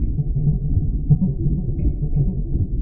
RI_ArpegiFex_85-03.wav